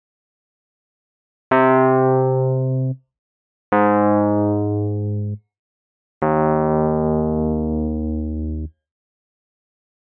Key written in: E♭ Major
Each recording below is single part only.